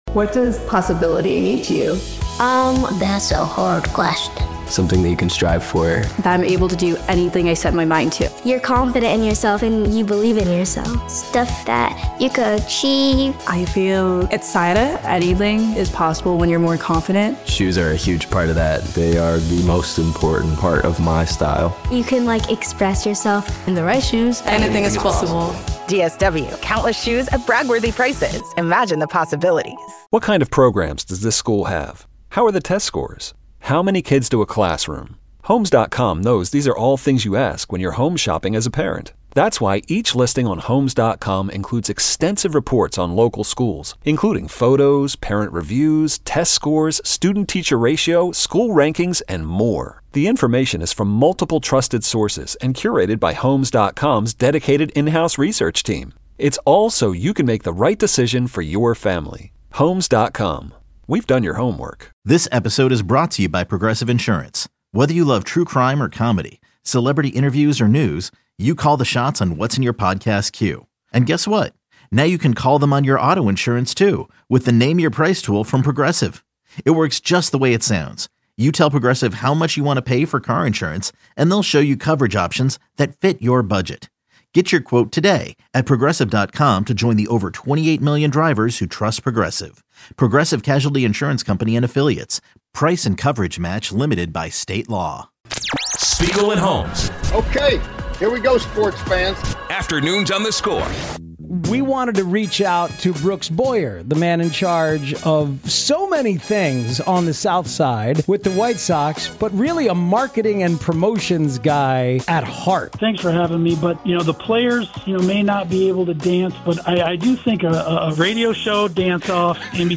Chicago sports talk with great opinions, guests and fun.
Catch the show live Monday through Friday (2 p.m. - 6 p.m. CT) on 670 The Score, the exclusive audio home of the Cubs and the Bulls, or on the Audacy app.